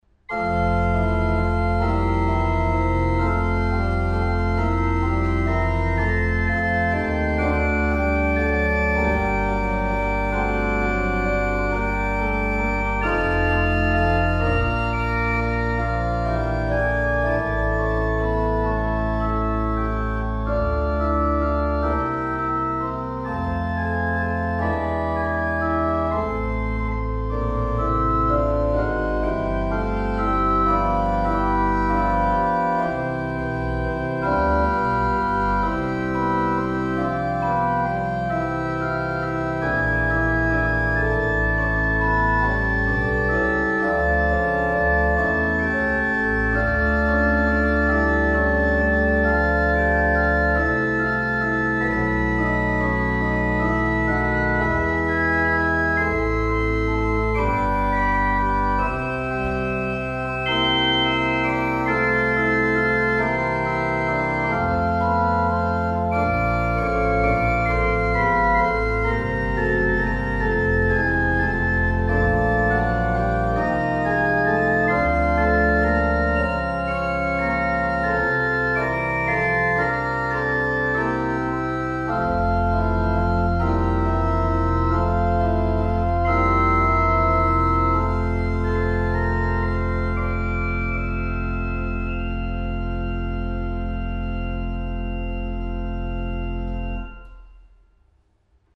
Hur låter orglarna? Från varje orgel  finns ljudexempel.
Nilivaara kyrka                                                                        Orgeln i Nilivaara kyrka